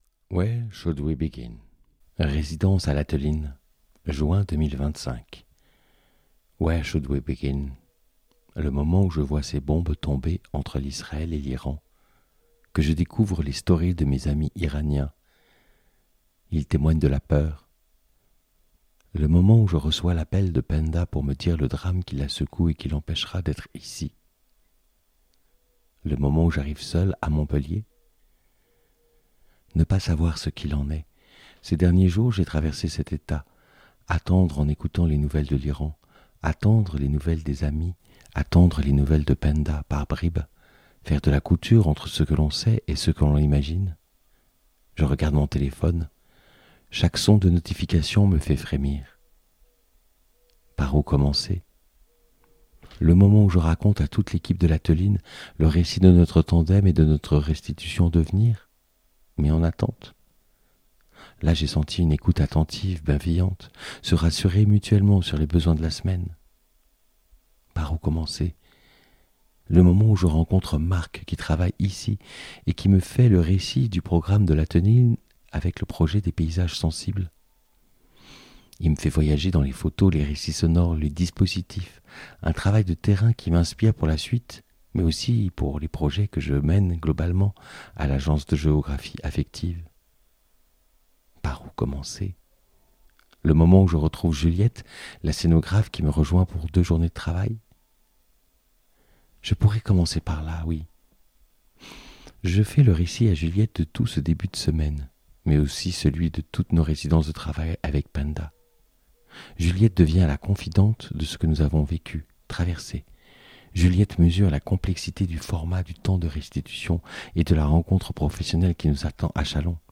récit sonore